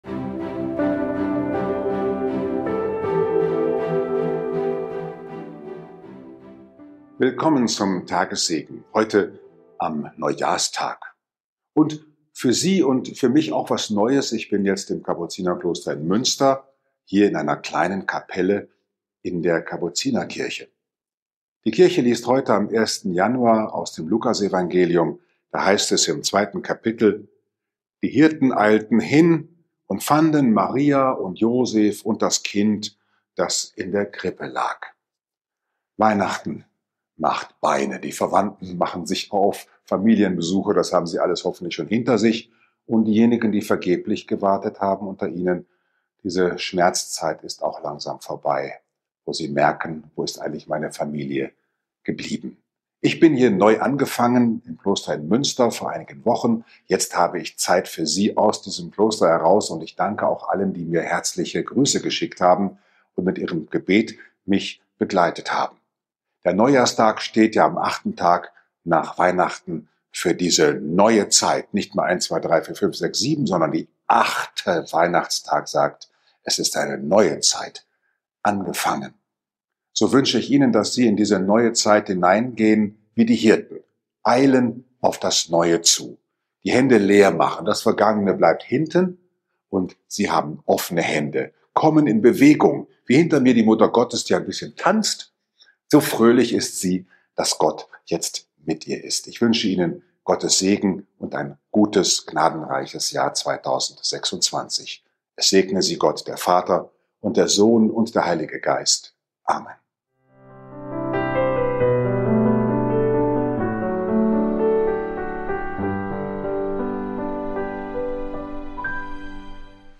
den Tagessegen aus einer Kapelle in der Kapuzinerkirche in Münster.